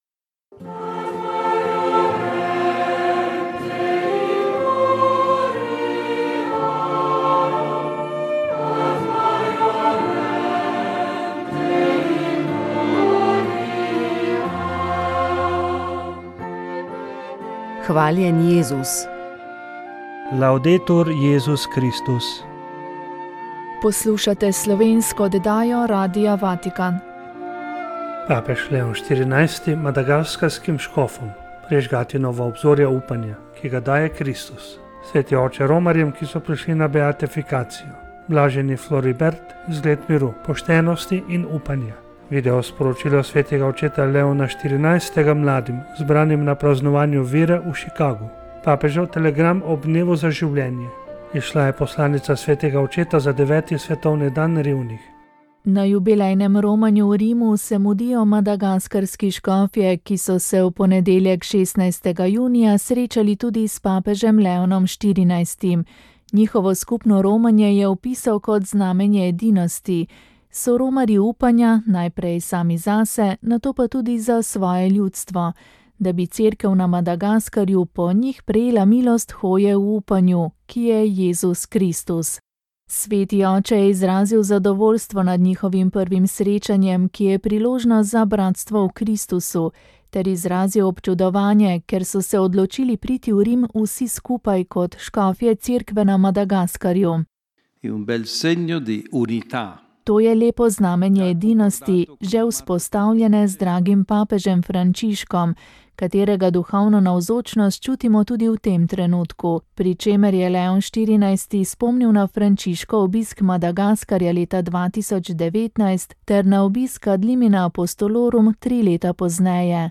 Rožni venec VEČ ...